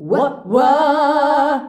UAH-UAAH D.wav